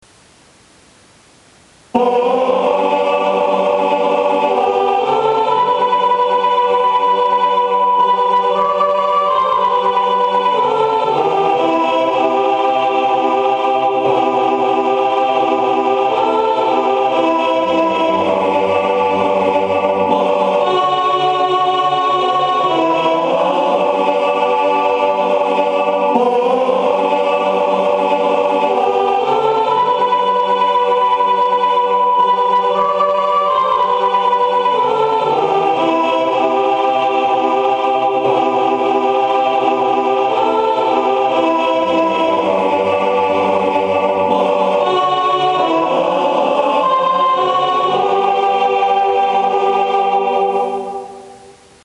160916_sopraan.MP3 (406.1 KB)